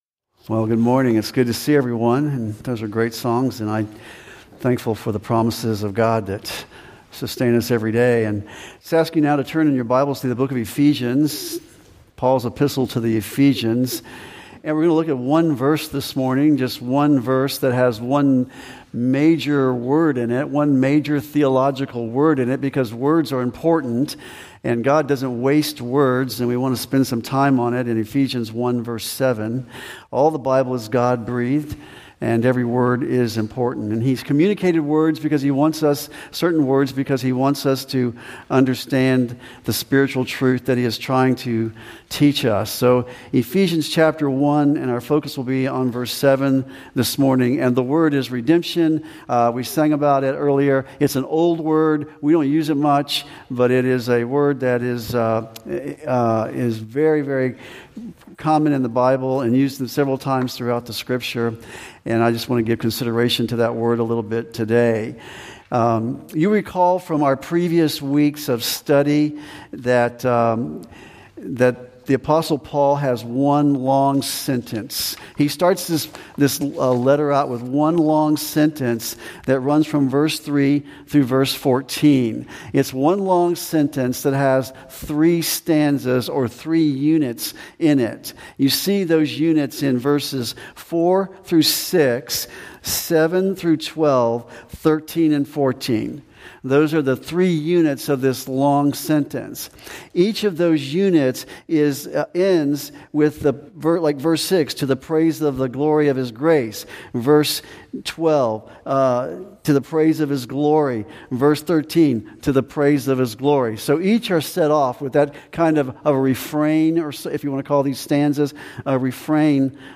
2025-06-29-AM-Sermon.mp3